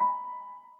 piano04.ogg